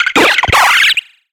Cri de Piclairon dans Pokémon Soleil et Lune.